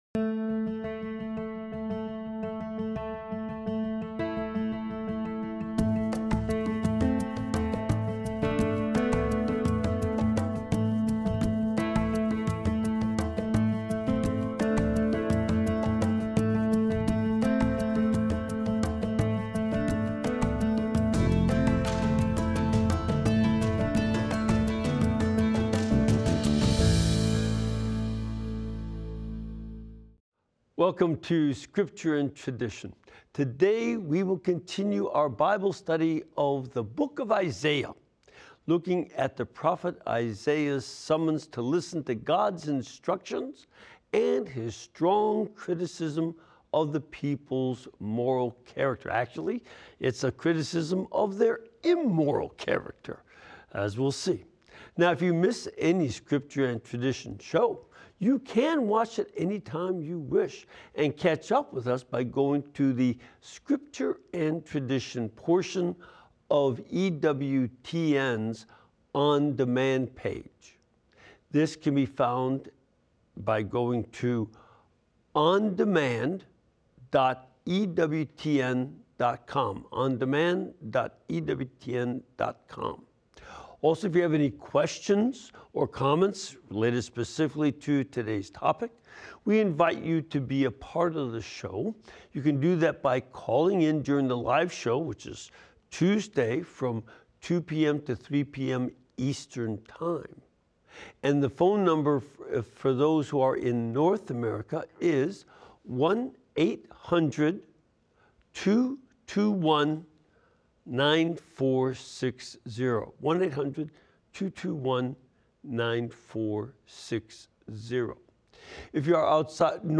EWTN Favorite and Scripture Scholar Fr. Mitch Pacwa analyzes Church Traditions and Teachings in light of Sacred Scripture during this weekly live program.